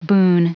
Prononciation du mot boon en anglais (fichier audio)
Prononciation du mot : boon